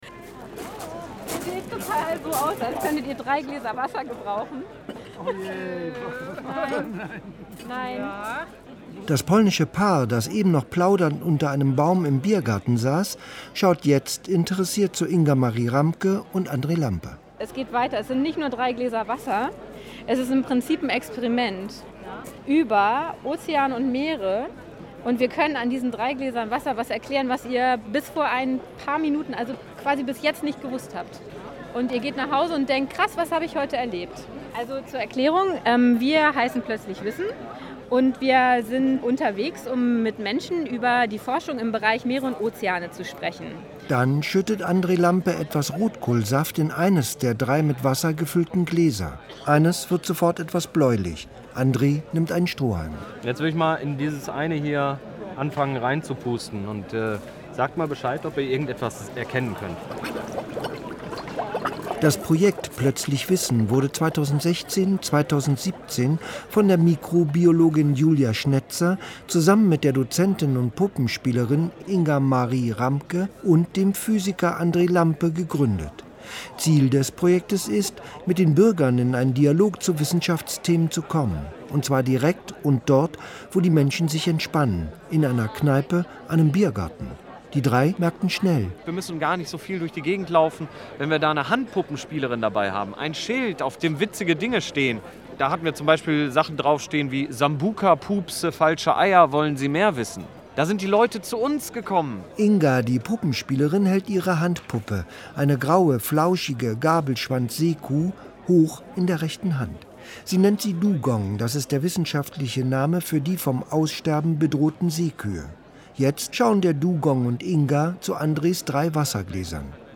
Im Eschenbräu gab es live fürs Radio das Rotkohl Experiment.
Der Deutschlandfunk Kultur war nämlich mit auf Tour und hat sein Mikro ganz nah an uns und unsere Gäste gehalten.
Die Biergartenatmosphäre ist nämlich besonders schön eingefangen. Wissenschaftkommunikation ganz ohne Jacke und draußen mit Dugong und Kaltgetränken.